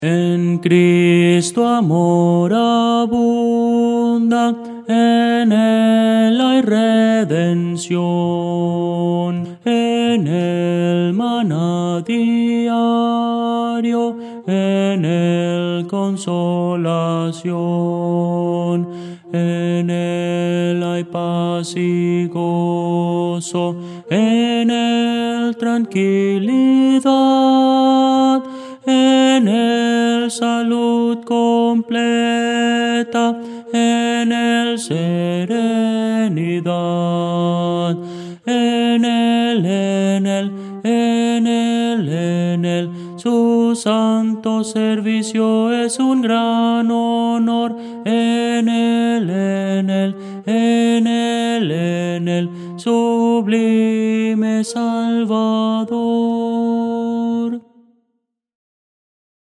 Voces para coro
Tenor – Descargar